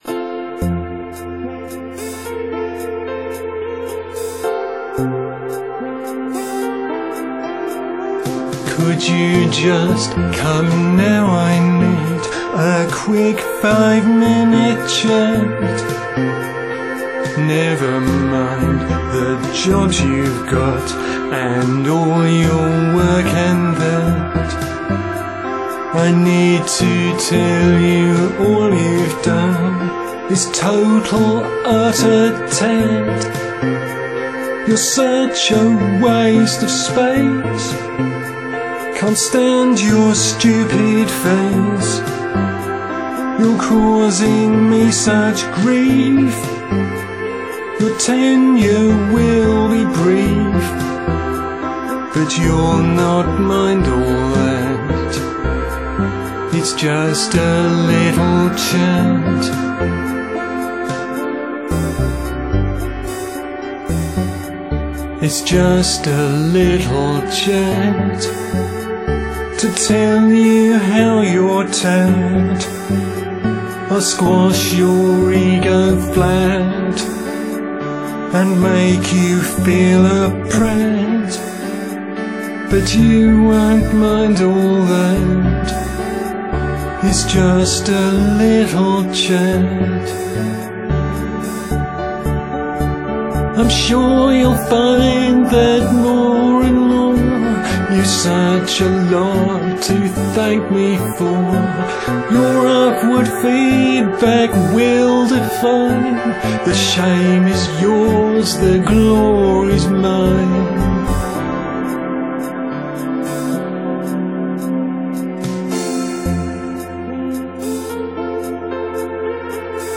A little chat - The original recording of this one was also severely hit by technical gremlins, so again it was later re-recorded. Later still the original effects were lost when a faulty plugin was stripped out so another version had to be made with different effects, and a manually pitch corrected vocal.